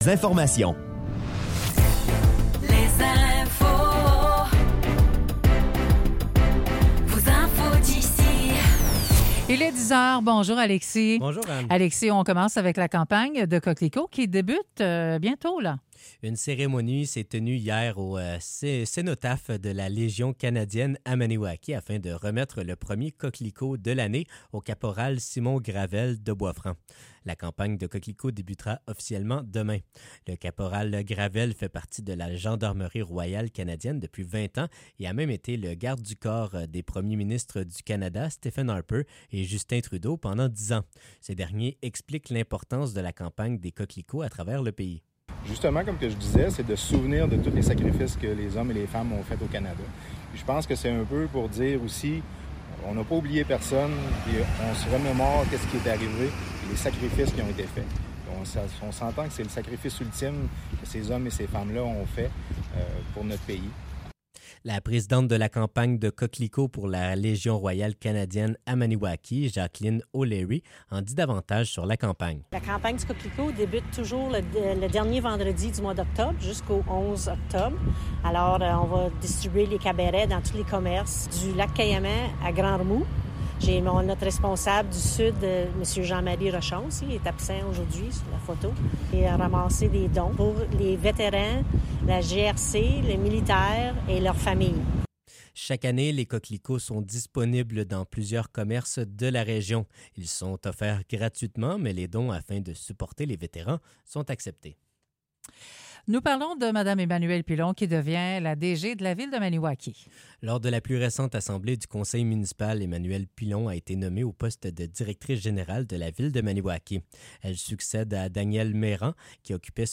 Nouvelles locales - 24 octobre 2024 - 10 h